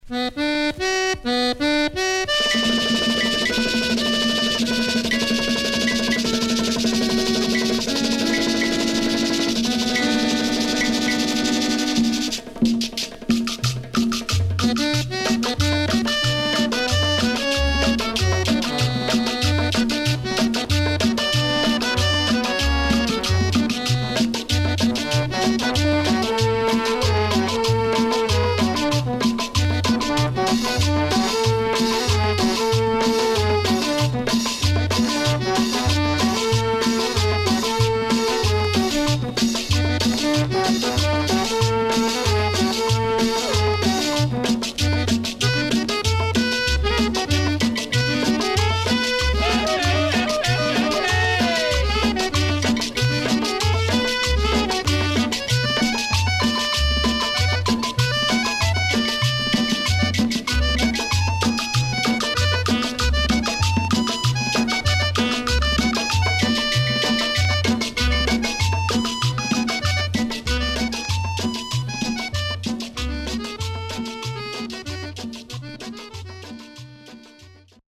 パーカッション主体にアコーディオンのメロディーライン。そしてベースラインはレゲエにも似た所があるというクンビア。